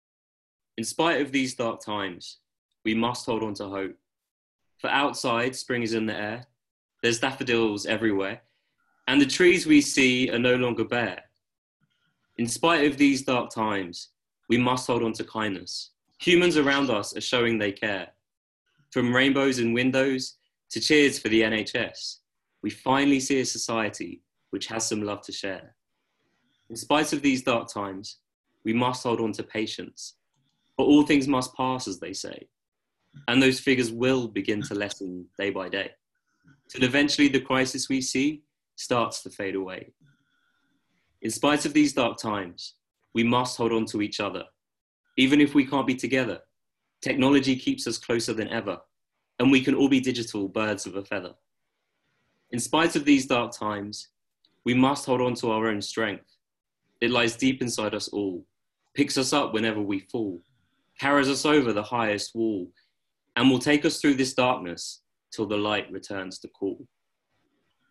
Listen to Jonny Benjamin's poem he performed at our Isolation Online Jam.